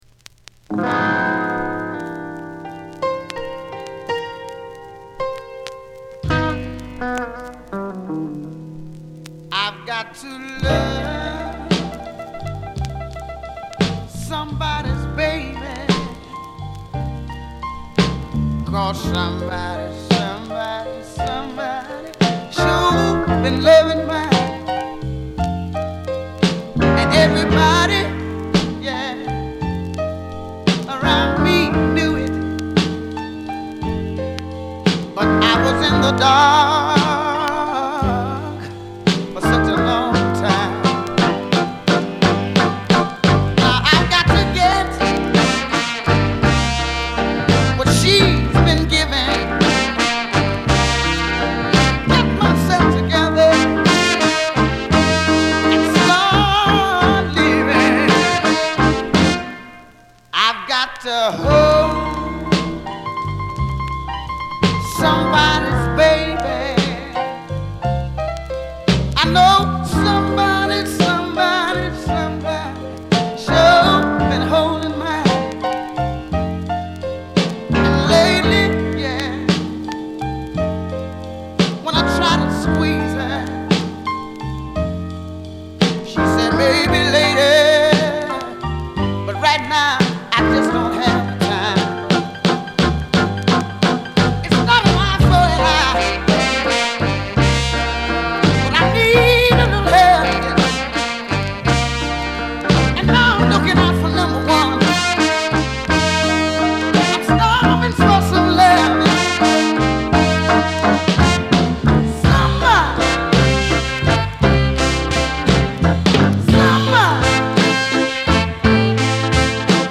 soul singers
bluesy narrative of a lover done wrong